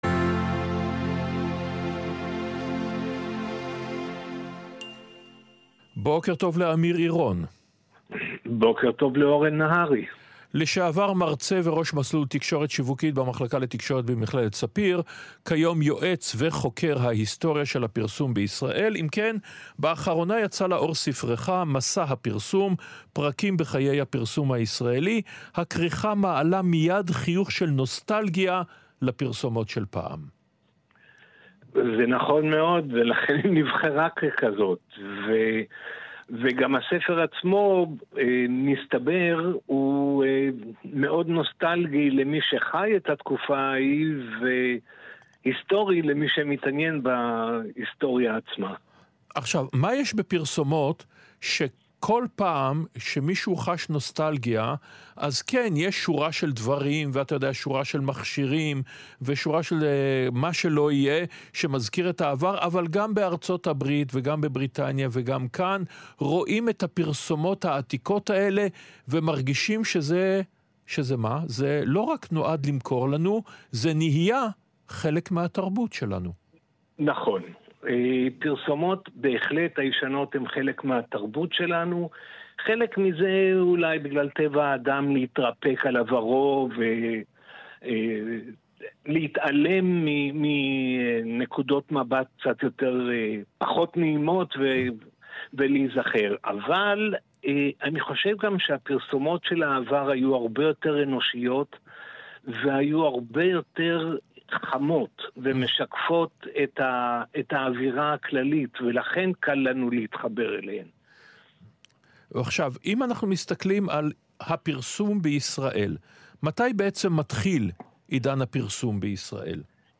רשת ב’ שיחה בתכנית “שבת עם אורן נהרי”